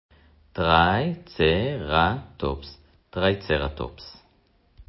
טרי-צ-ר-טופס